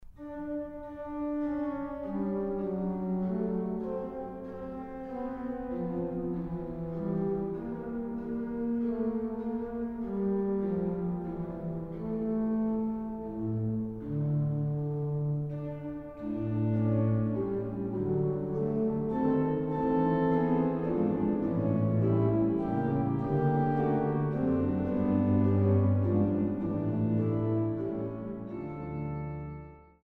Kaps-Orgel in Mariä Himmelfahrt zu Dachau